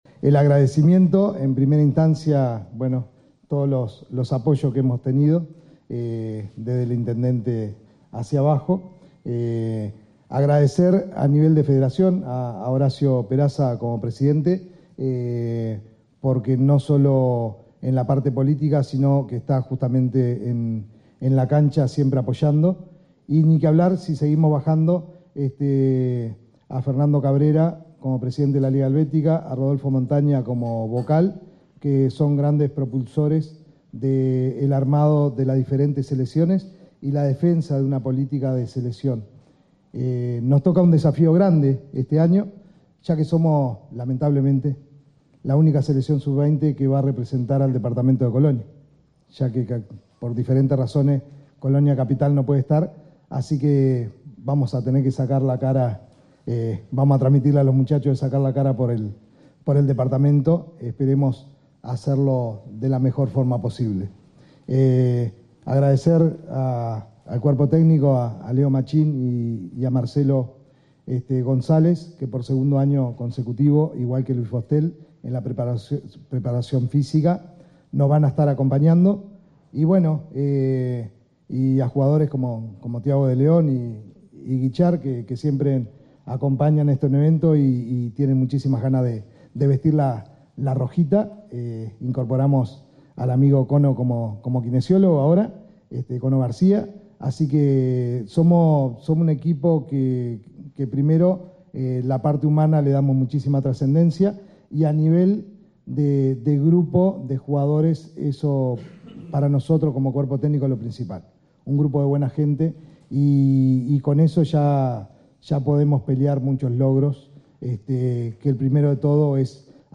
En la tarde de este miércoles, en el Salón de Actos de la Intendencia de Colonia, se realizó la presentación oficial de la Selección Sub 20 de la Federación de Fútbol de Colonia, que participará en el próximo Campeonato Nacional de la categoría.